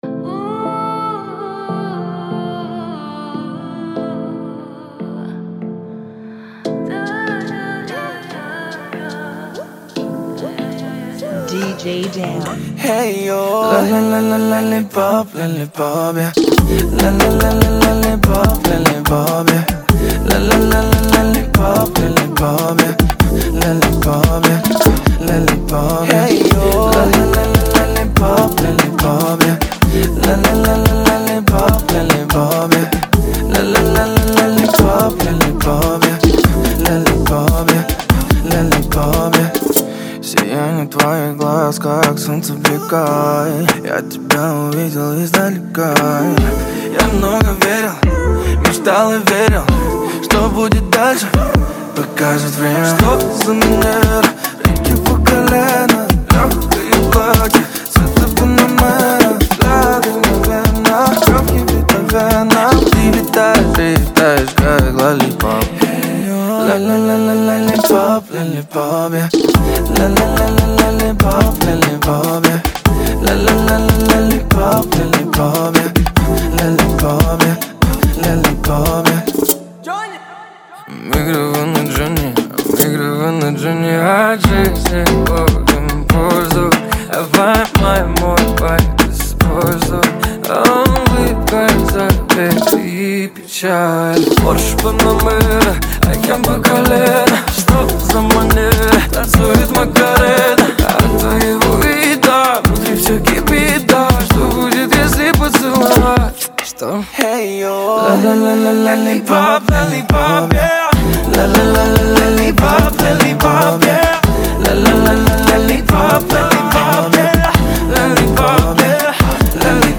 Genre: Bachata Remix